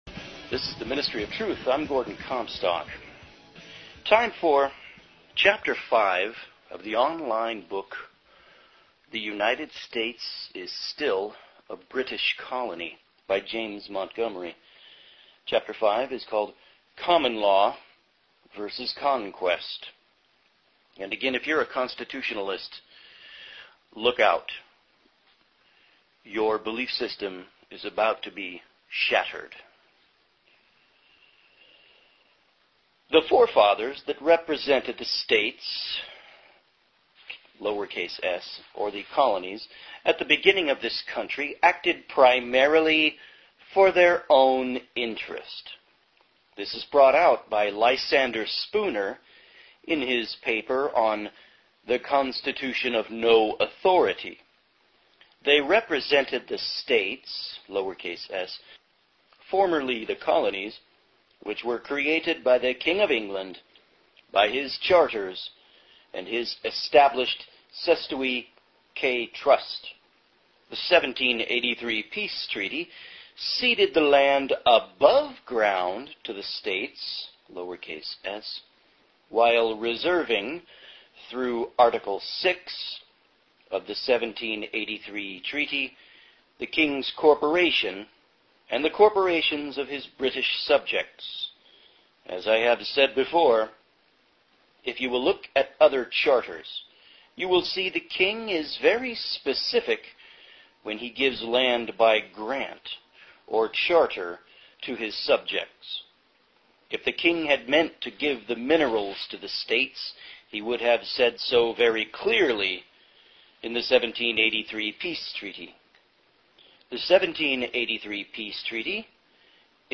Listen to the essay read by